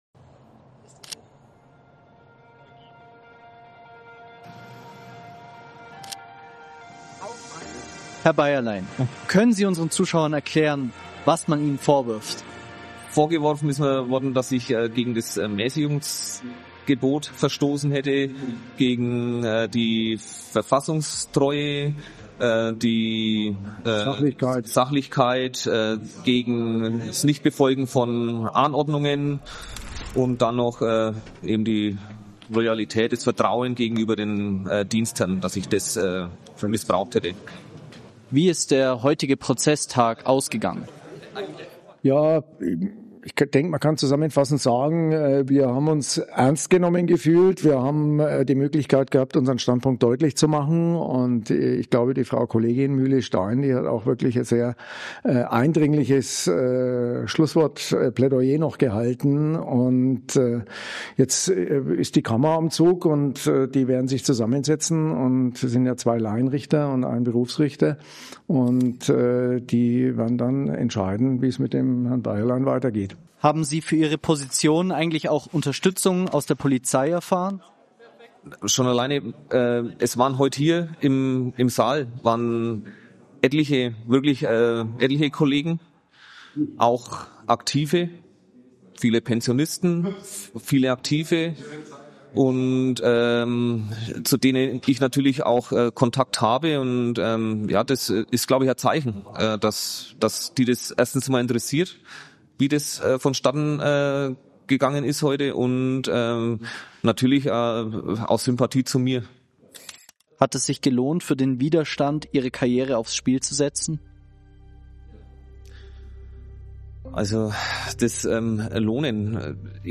AUF1 war vor Ort.